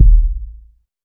KICK.58.NEPT.wav